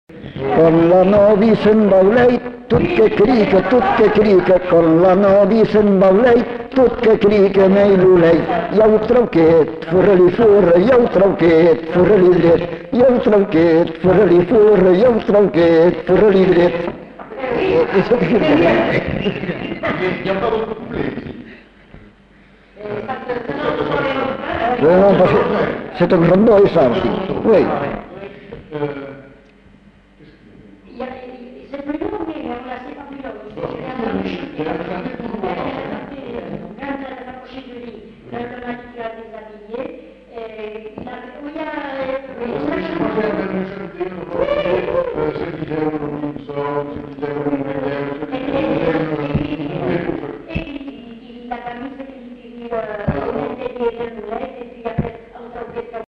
Lieu : Cazalis
Genre : chant
Type de voix : voix mixtes
Production du son : chanté
Danse : rondeau
Classification : chansons de neuf